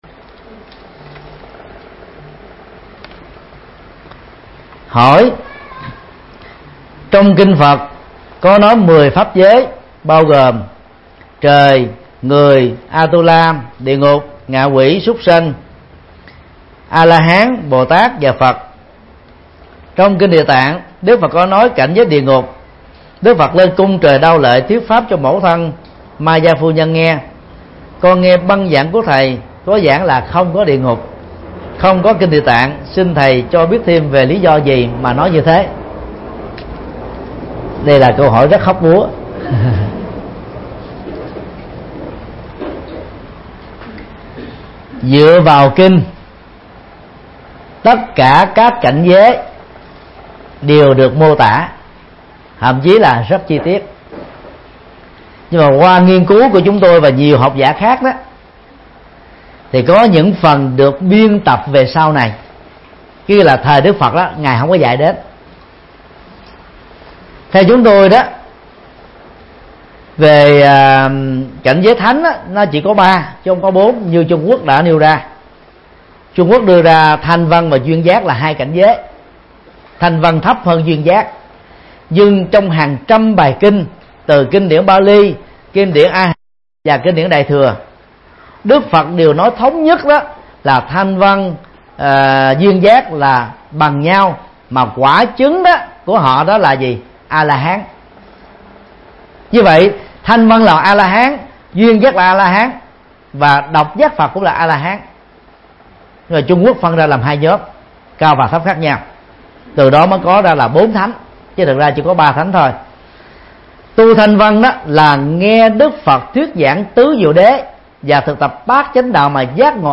Mp3 Vấn đáp: Cảnh giới địa ngục – Thầy Thích Nhật Từ Giảng tại chùa Hoa Nghiêm, Paris, Pháp, ngày 28 tháng 6 năm 2015